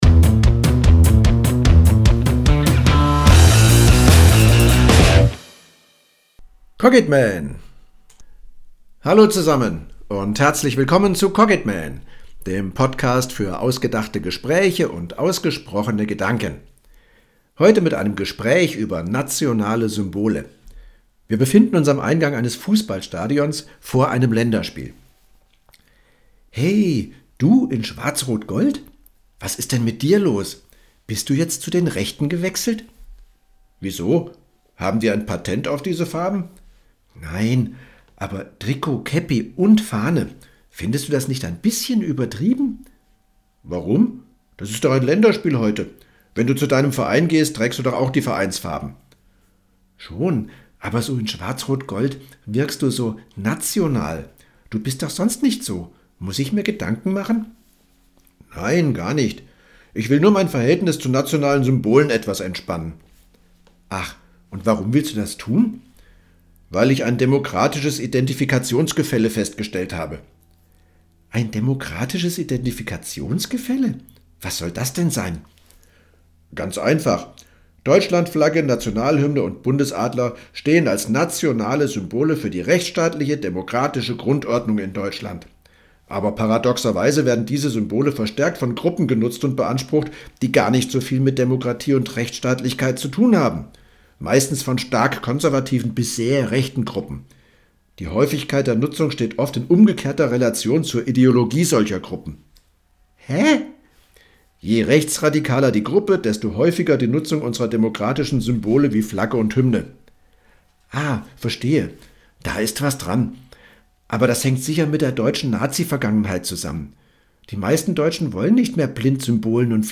Dialog_Nationale-Symbole.mp3